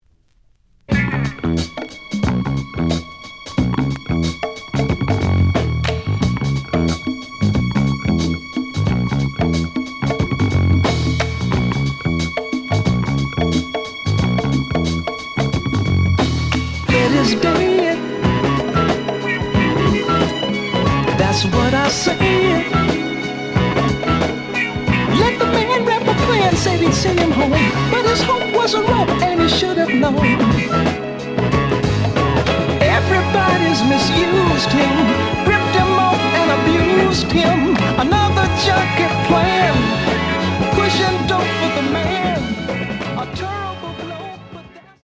The audio sample is recorded from the actual item.
●Genre: Soul, 70's Soul
Slight damage on both side labels. Plays good.)